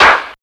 NYC125CLAP-R.wav